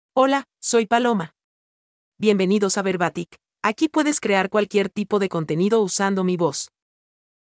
Paloma — Female Spanish (United States) AI Voice | TTS, Voice Cloning & Video | Verbatik AI
Paloma is a female AI voice for Spanish (United States).
Voice sample
Listen to Paloma's female Spanish voice.
Paloma delivers clear pronunciation with authentic United States Spanish intonation, making your content sound professionally produced.